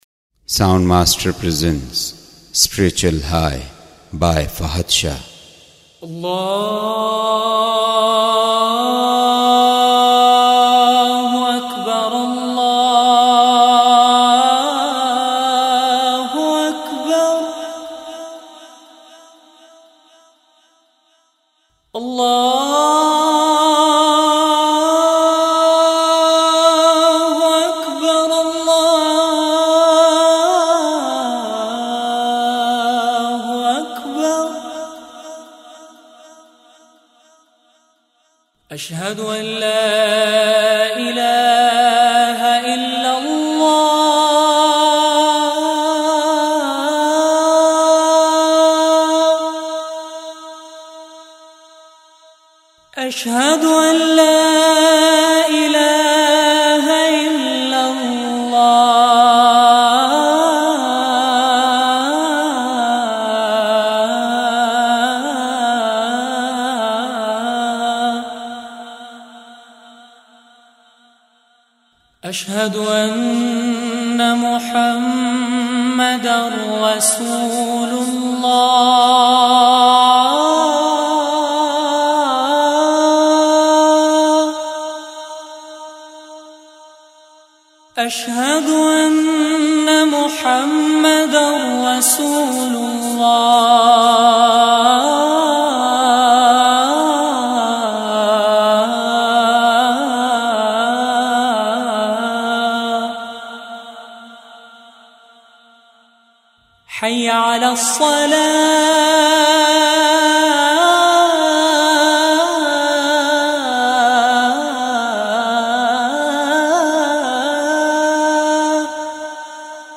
Azane Fajr And Dua Listen Online & Download MP3